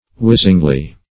whizzingly - definition of whizzingly - synonyms, pronunciation, spelling from Free Dictionary Search Result for " whizzingly" : The Collaborative International Dictionary of English v.0.48: Whizzingly \Whiz"zing*ly\, adv. With a whizzing sound.